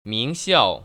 [míngxiào] 밍시아오  ▶